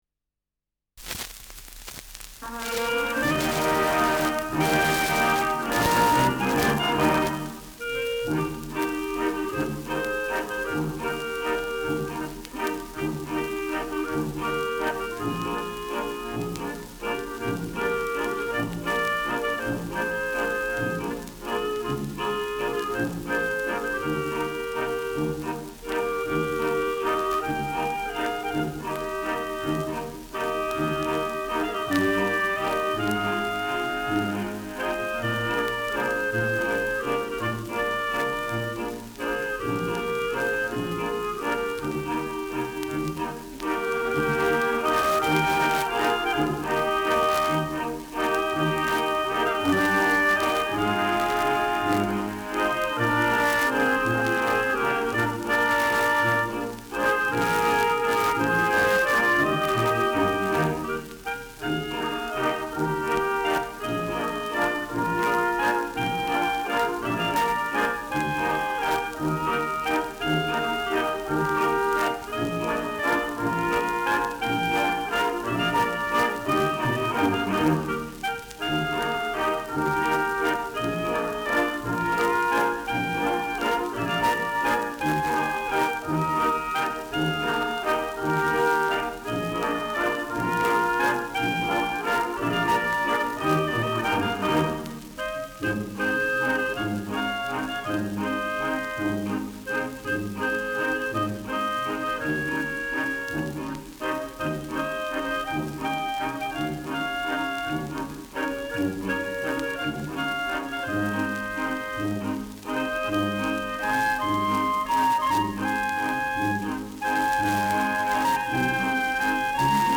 Schellackplatte
Mit Geigennachschlag.